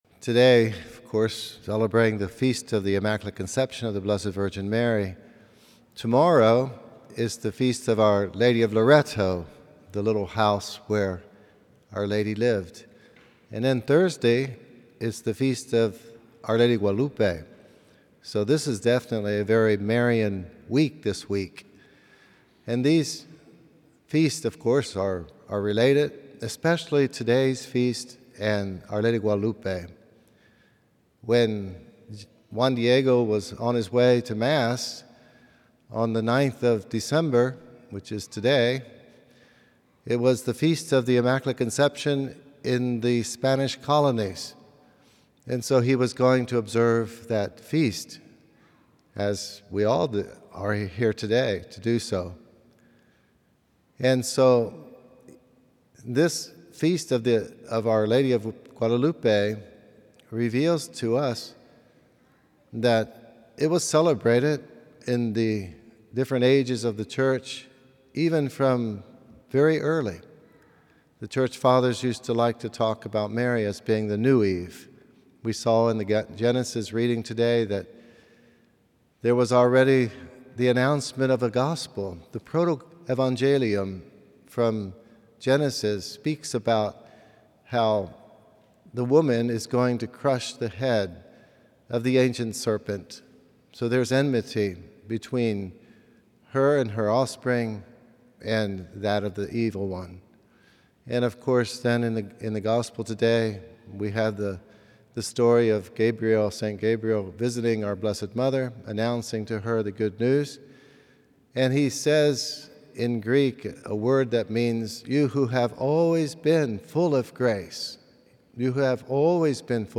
A homily from the series "Homilies."